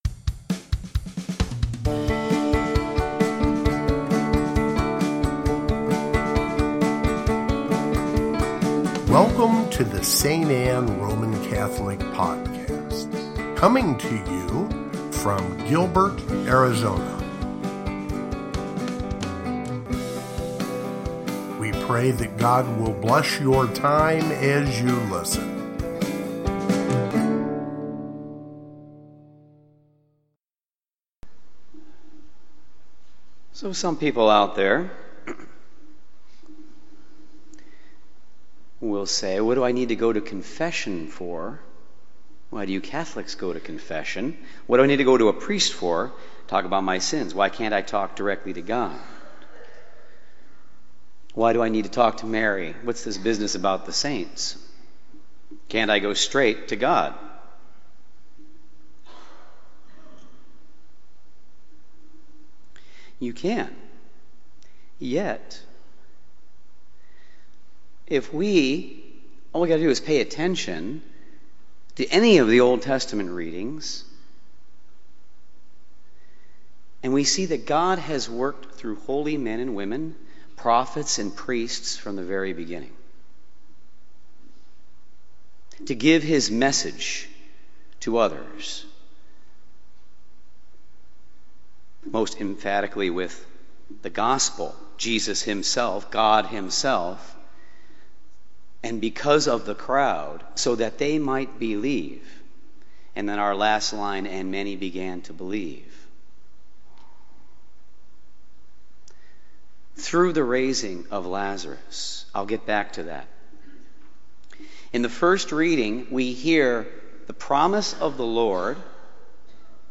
Fifth Sunday of Lent (Homily) | St. Anne